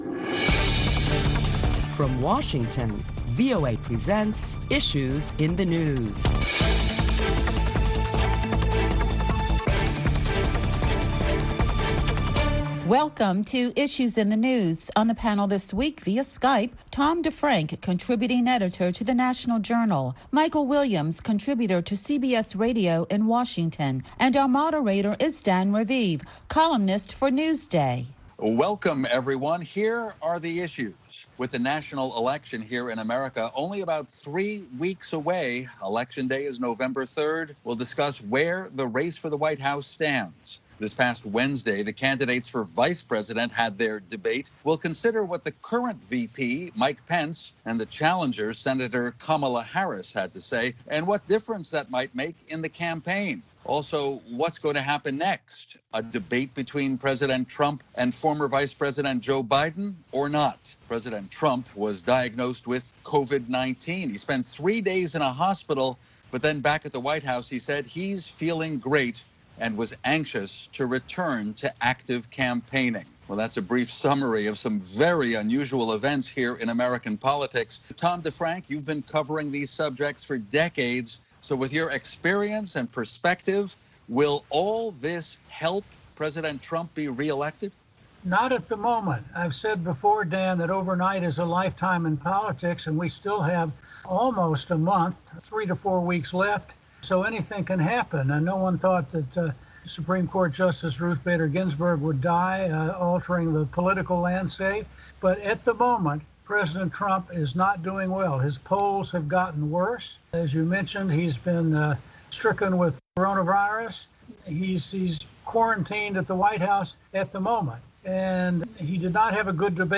A panel of prominent Washington journalists for Issues in the News deliberate the top stories of the week including an outbreak of coronavirus at the White House that infected President Donald Trump and many others.